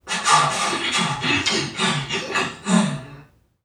NPC_Creatures_Vocalisations_Robothead [31].wav